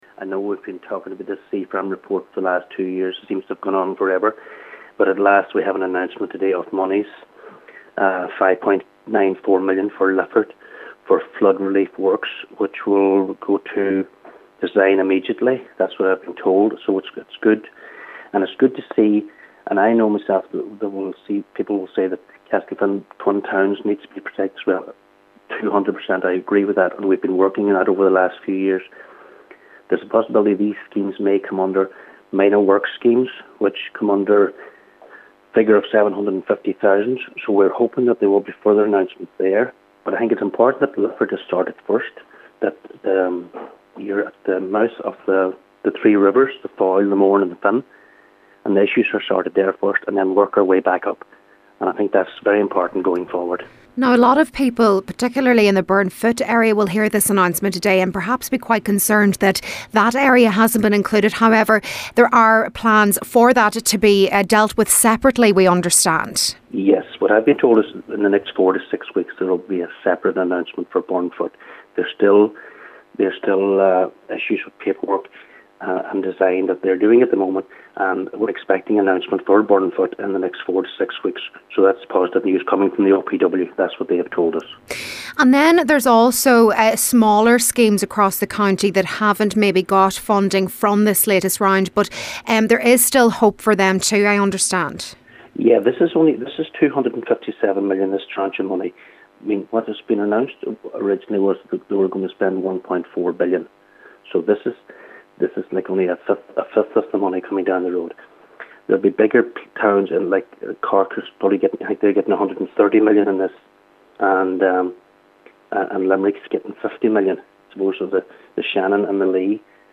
Cllr. Martin Harley believes addressing issues at Lifford first is the best approach: